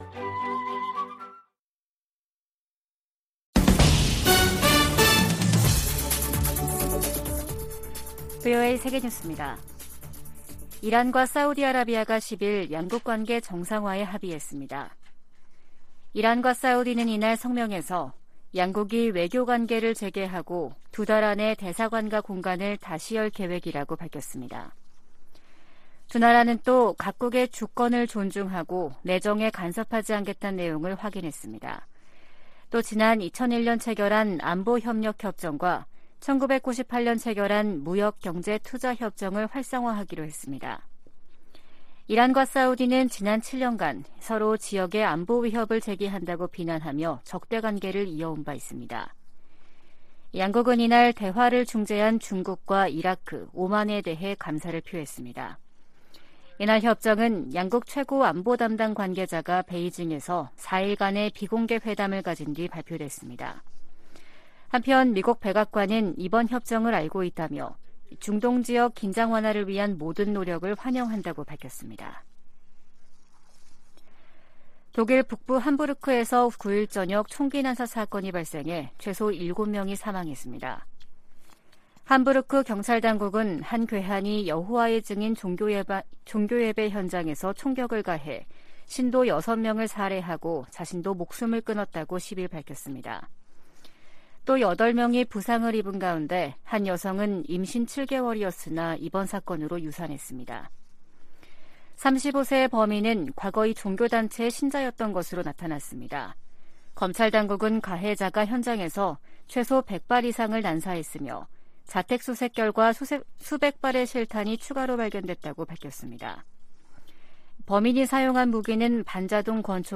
VOA 한국어 아침 뉴스 프로그램 '워싱턴 뉴스 광장' 2023년 3월 11일 방송입니다. 북한이 9일 신형 전술유도무기로 추정되는 탄도미사일을 최소 6발 서해로 발사했습니다. 미 국무부는 북한의 미사일 발사를 규탄하며, 대화에 열려 있지만 접근법을 바꾸지 않을 경우 더 큰 대가를 치르게 될 것이라고 경고했습니다. 미국 전략사령관이 의회 청문회에서 북한의 신형 대륙간탄도미사일로 안보 위협이 높아지고 있다고 말했습니다.